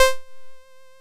synt6.wav